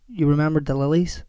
Examples of the most common tunes in the IViE corpus (read sentences)
Fall+Rising
Belfast male
fallrise-belfast-male.wav